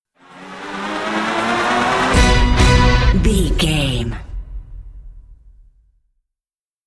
Epic / Action
Aeolian/Minor
strings
drums
cello
violin
percussion
orchestral hybrid
dubstep
aggressive
energetic
intense
bass
synth effects
wobbles
driving drum beat
epic